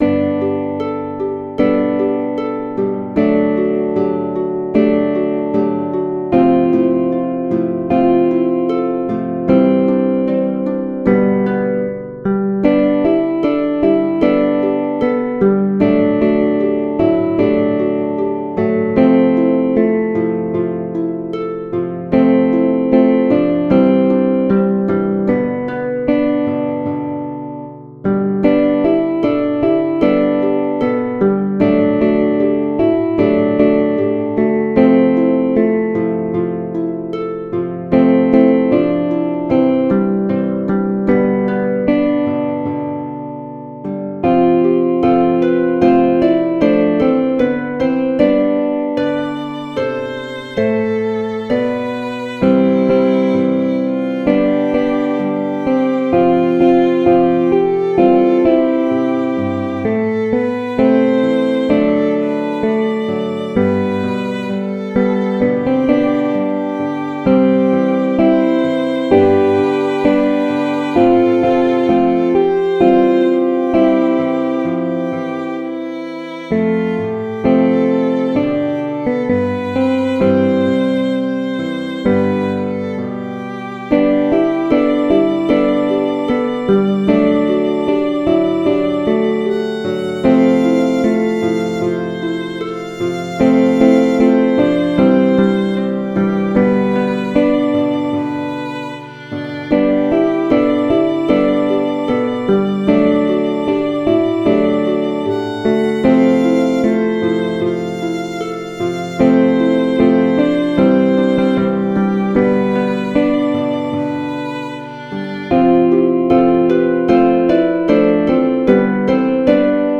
Worship song for 2026              The Voice of God